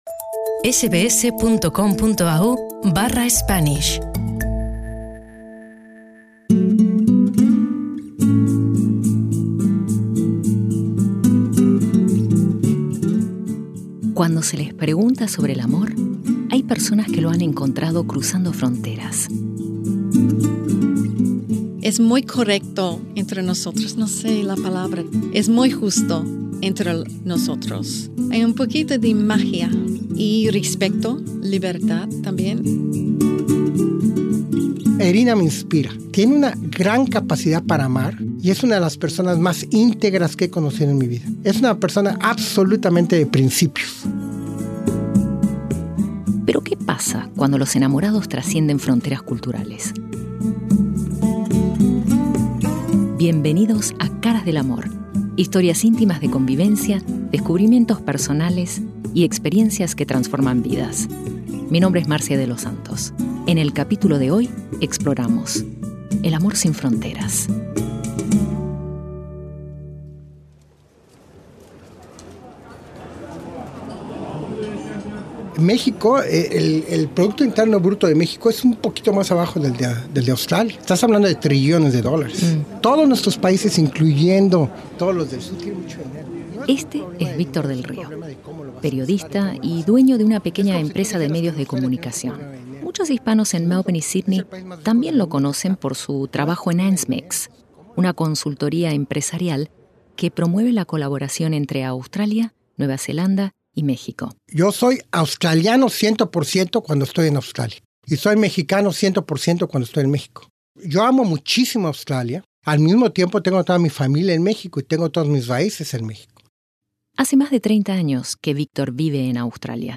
Biografías de los entrevistados: